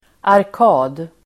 Uttal: [ark'a:d]